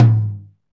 TOM R B M0TR.wav